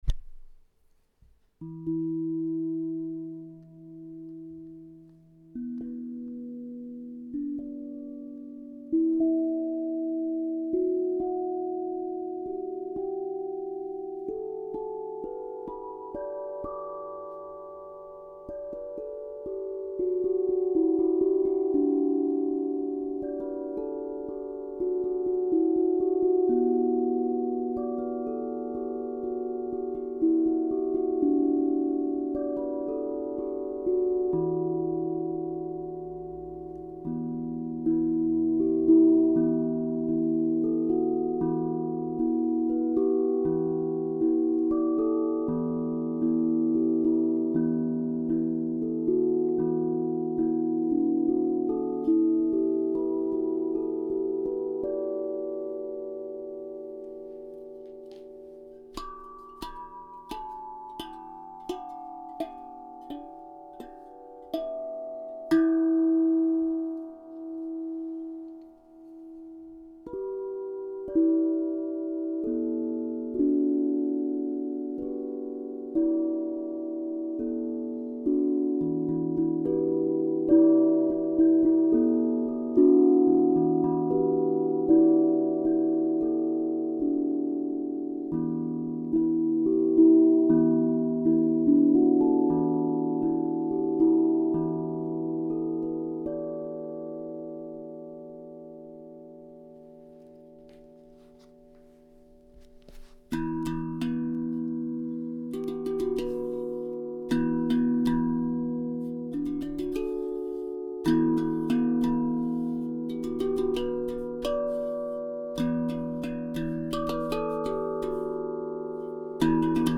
Hirondelles, Mi 432 hz - Osb Drum
Parfait pour vous aider à vous relaxer, à méditer et vous connecter à votre paix intérieure et vos pouvoirs d’auto-guérison par sa vibration joyeuse et douce.
hirondelles-en-mi-432-hz.mp3